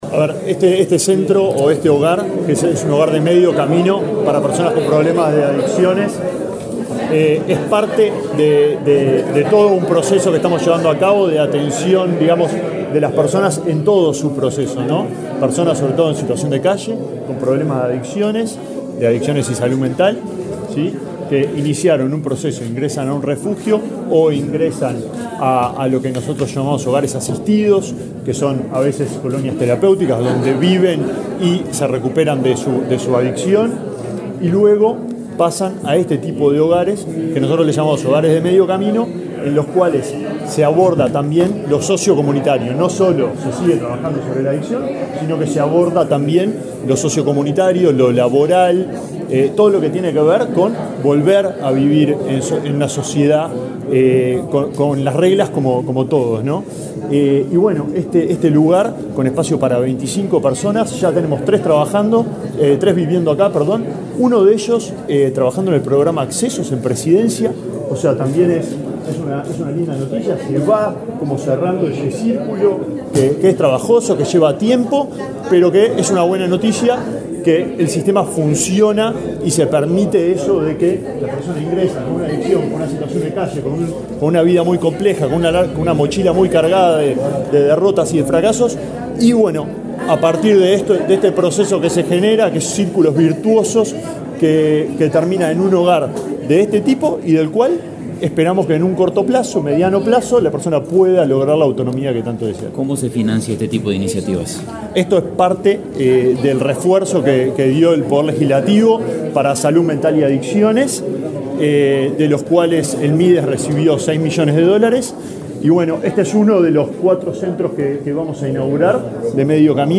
Declaraciones del ministro de Desarrollo Social, Alejandro Sciarra
El ministro de Desarrollo Social, Alejandro Sciarra, dialogó con la prensa, luego de participar en la inauguración de una casa de medio camino en régimen de 24 horas para personas con alta vulnerabilidad social y en tratamiento por consumo de sustancias de forma problemática. El nuevo servicio está ubicado en el bario Ciudad Vieja, en Montevideo.